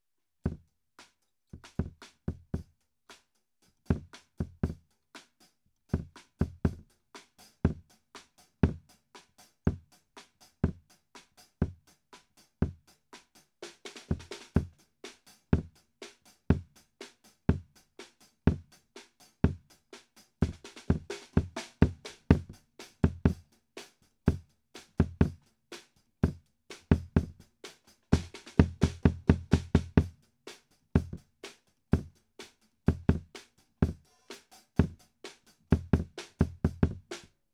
As mentioned in “part 1” I used a Shure PG 52 for the front of the Kick.
This is the PG 52 on its own
It sounds decent on its own.
kick-pg52.m4a